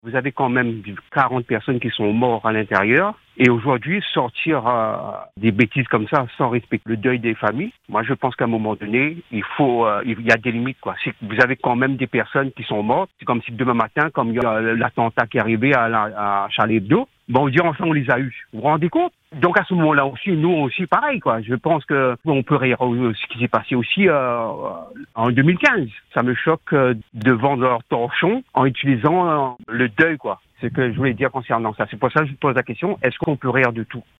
Cet auditeur que vous allez entendre ne décolère pas.
Voici le témoignage de cet auditeur, choqué par cette caricature.